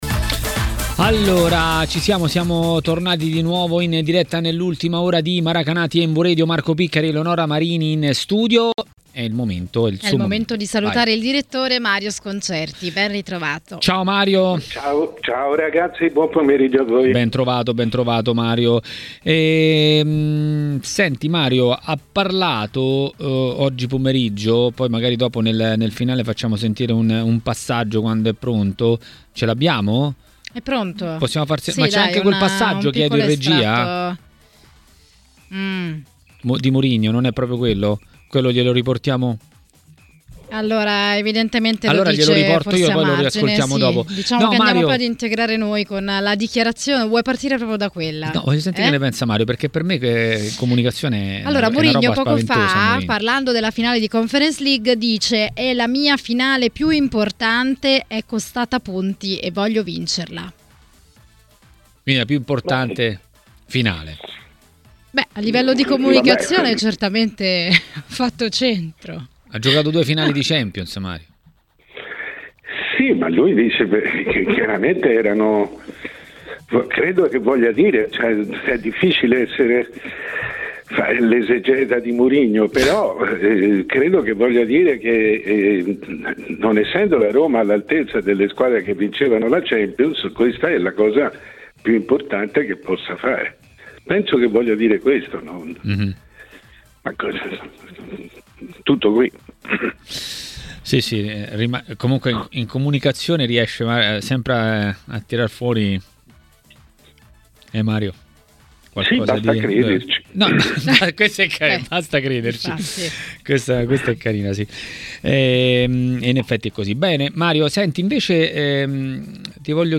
Ai microfoni di Tmw Radio, è intervenuto il direttore Mario Sconcerti.